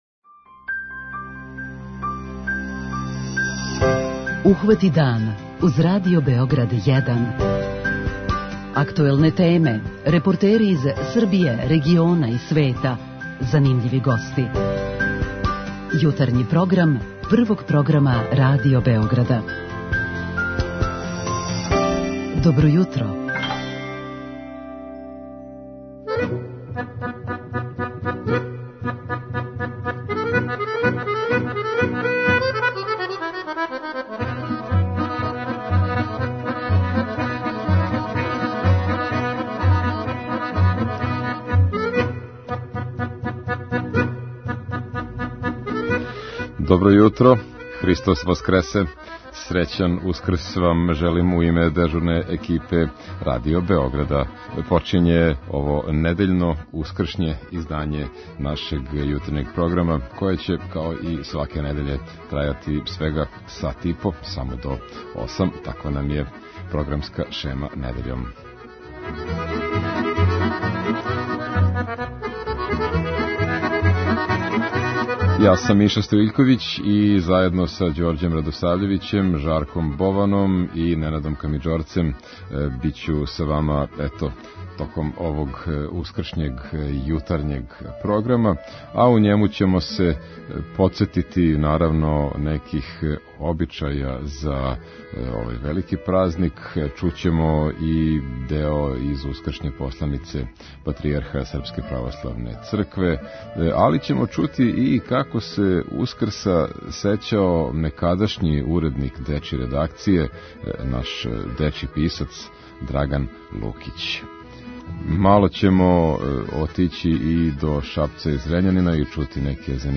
Као и сваке године за Ускрс, у јутарњем програму ћемо чути изводе из посланице патријарха Српске православне цркве. Ове године чућемо и тонски запис из наше богате архиве у којем се наш познати дечји писац и некадашњи уредник дечје редакције Радио Београда Драган Лукић присећа како је с породицом дочекао Ускрс 1944. с једним јединим јајетом у кући.